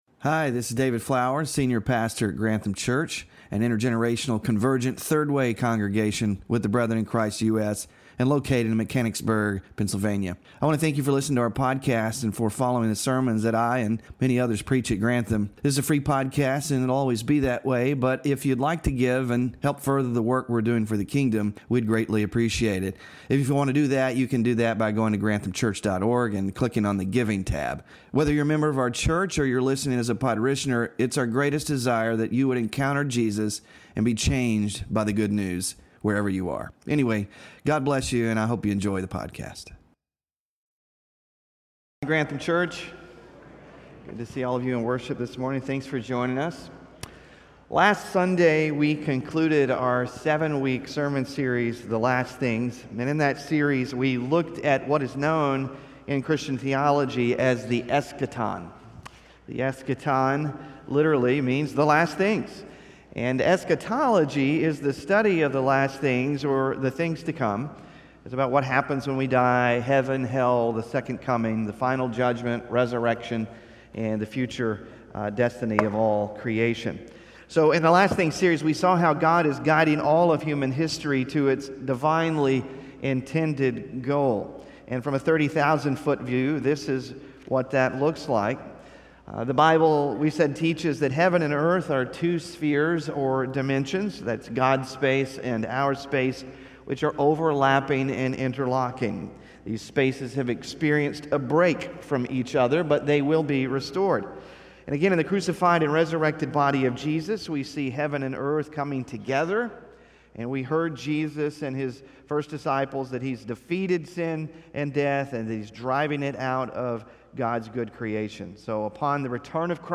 WORSHIP RESOURCES THE LAST THINGS - RESPONDING TO FAQs – SERMON SLIDES (6-22-25) BULLETIN (6-22-25)